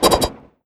active_knives.wav